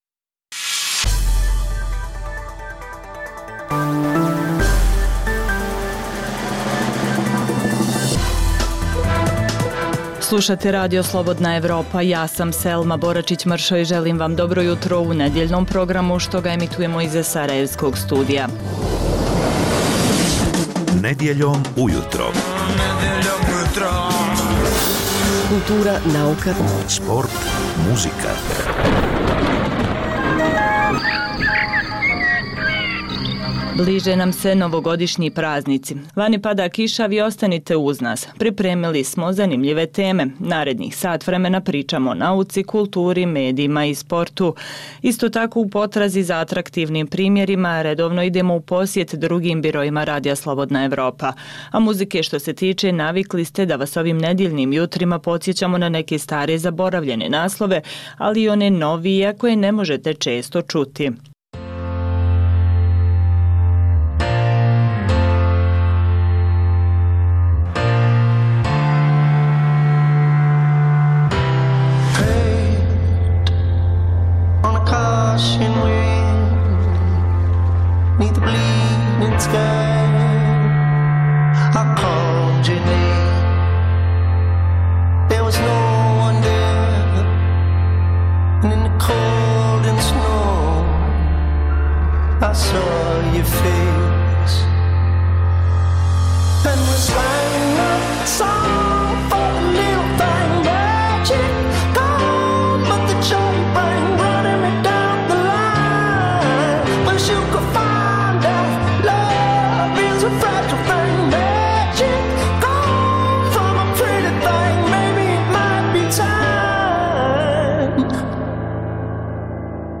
Nedjeljni jutarnji program za Bosnu i Hercegovinu. Poslušajte intervju sa zanimljivim gostom i, uz vijesti i muziku, pregled novosti iz nauke i tehnike, te čujte šta su nam pripremili novinari RSE iz regiona.